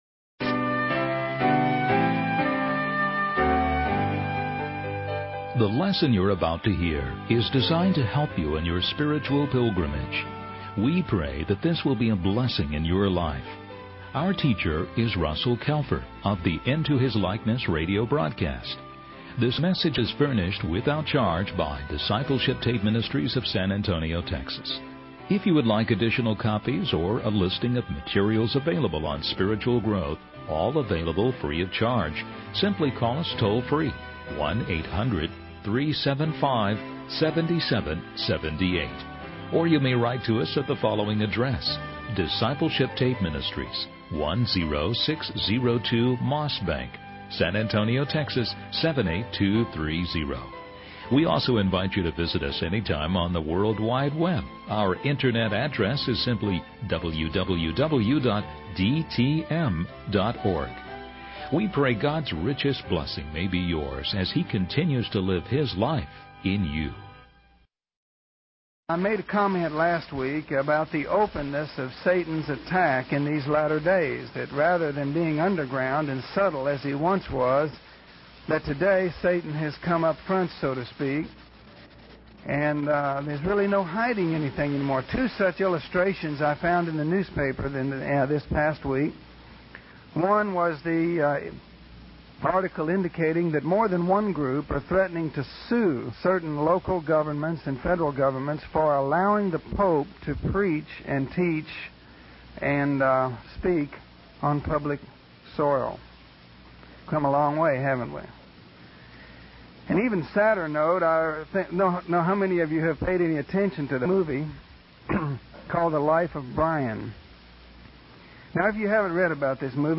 In this sermon, the speaker emphasizes the importance of passing down the teachings of God to future generations. He references Deuteronomy chapter 4, verses 9 and 10, which instruct believers to diligently keep their souls alert and not forget what God has done for them.